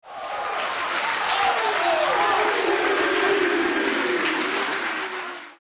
start_cheer_001.mp3